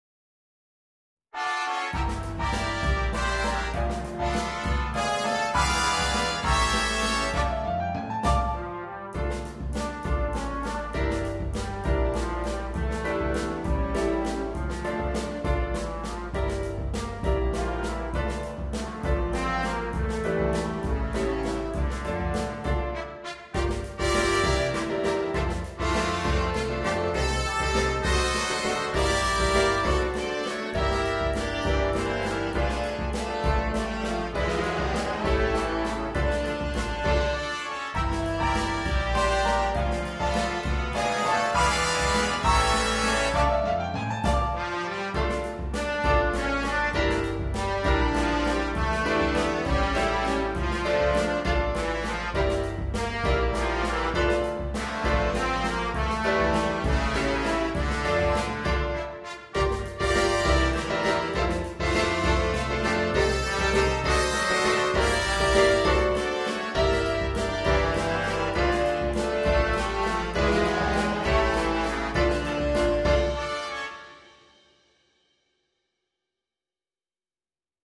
Ноты для биг-бэнда